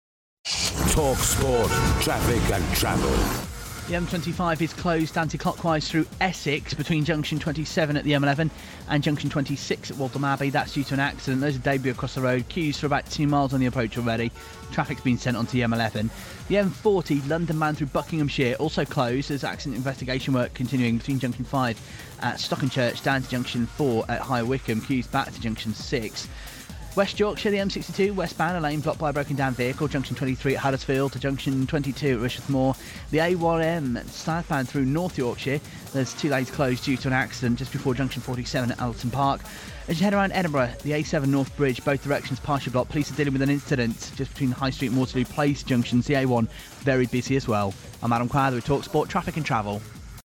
National talkSPORT Traffic and Travel bulletin, recorded Summer 2013.